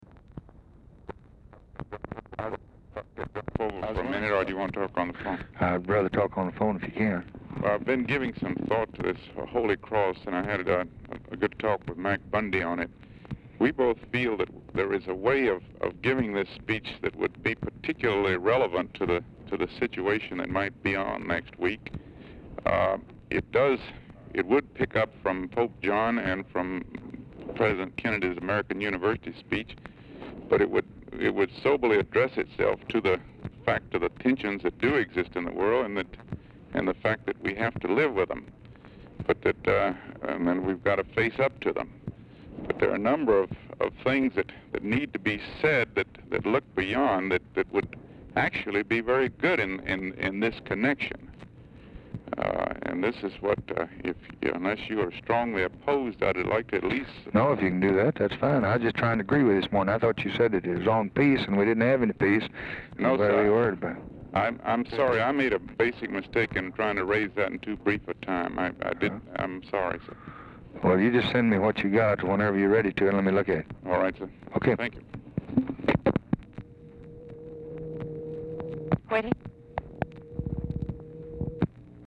Telephone conversation # 3636, sound recording, LBJ and DOUGLASS CATER, 6/6/1964, 5:45PM | Discover LBJ
Format Dictation belt
Location Of Speaker 1 Oval Office or unknown location